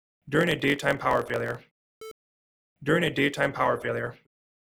What is this type of distortion called? No luck removing with de-clip / de-click / compress
Would you call this “crackle”?